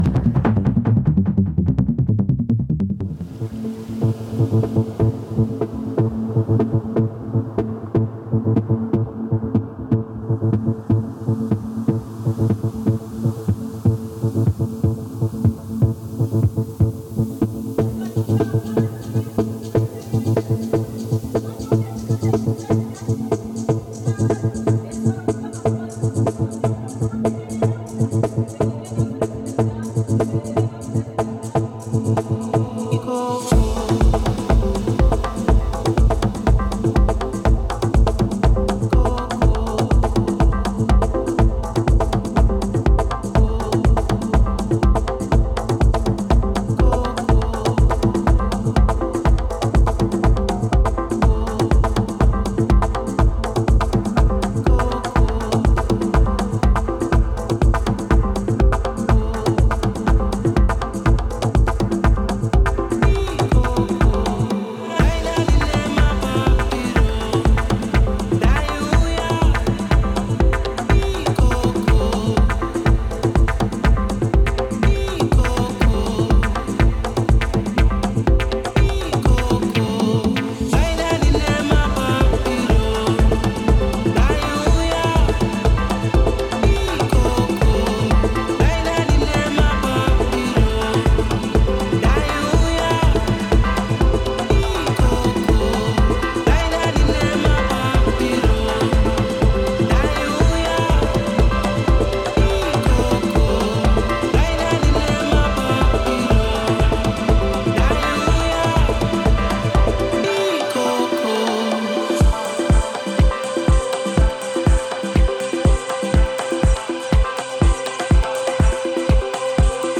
French-Congolese female producer/DJ
Bringing authenticity back to Afro house music
Afrobeats Dance House Pop